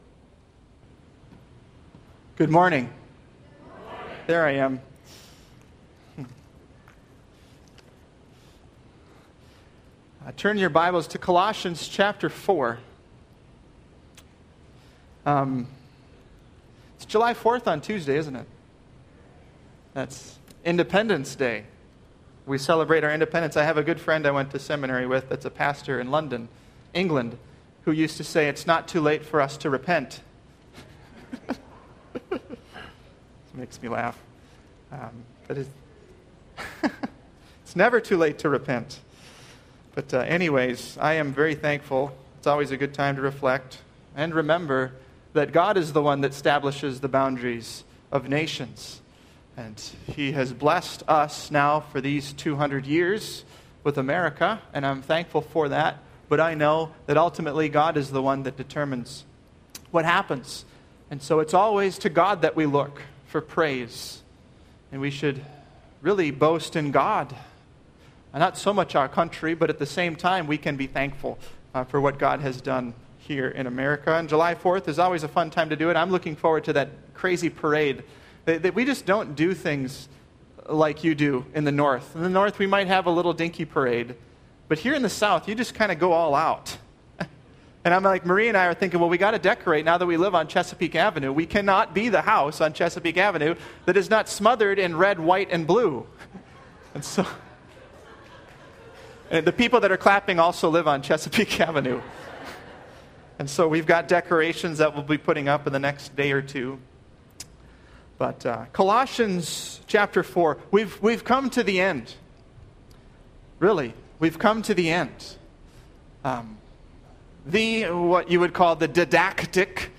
Sermons – Page 49 – Welcome to SNCCC